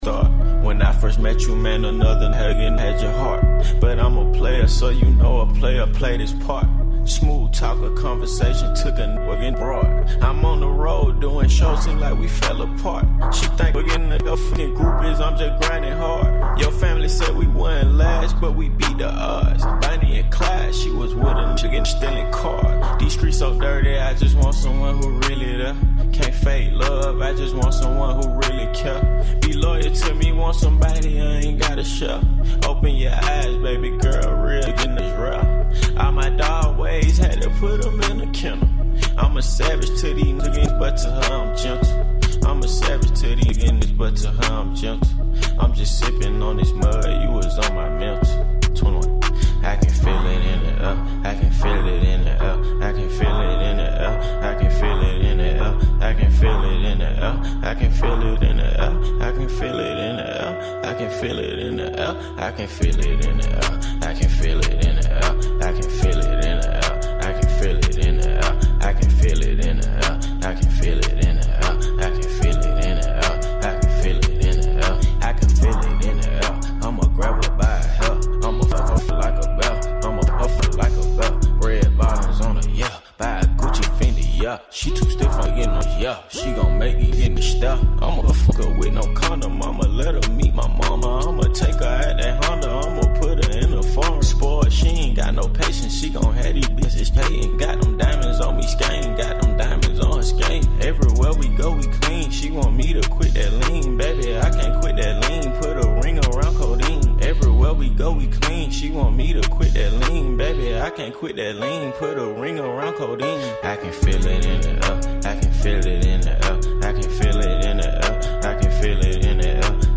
On this show, you’ll hear recent news, personal experiences, and a diverse selection of music. Youth Radio Raw is a weekly radio show produced by Bay Area high schoolers, ages 14-18.